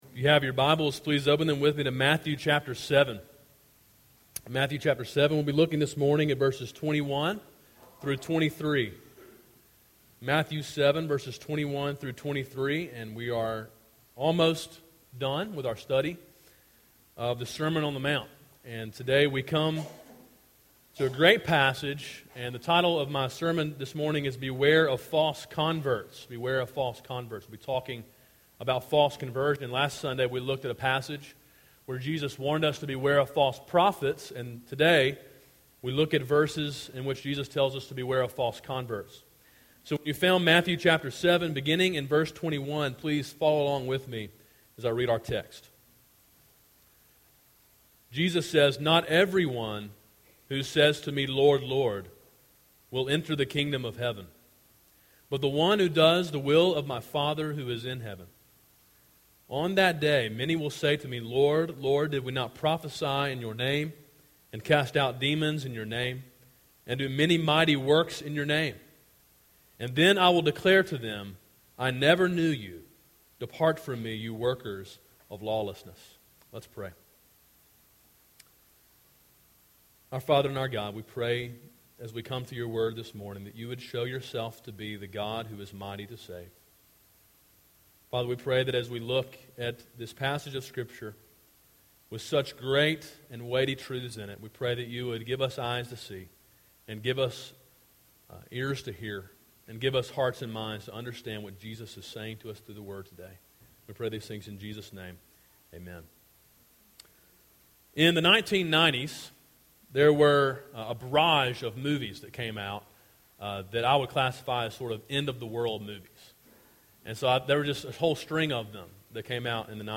A sermon in a series titled Sermon on the Mount: Gospel Obedience.